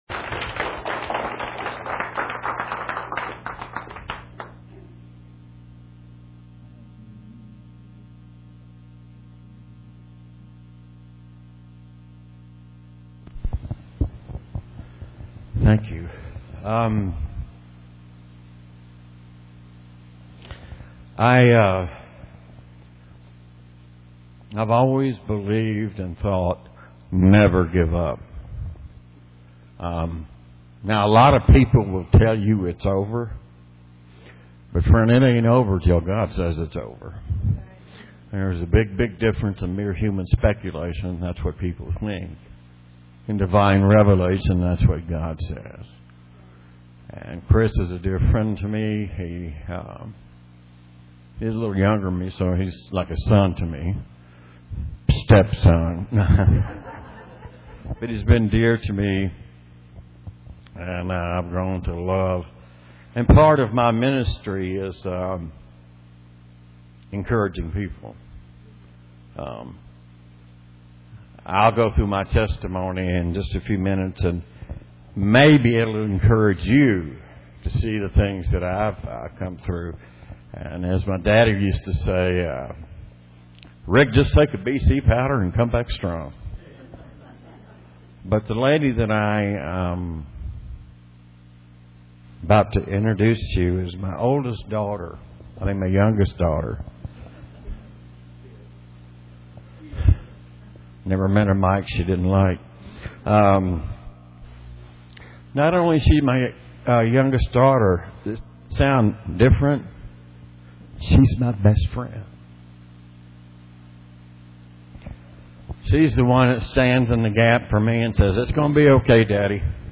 Worship Service October 5, 2014 |
Testimonies and Message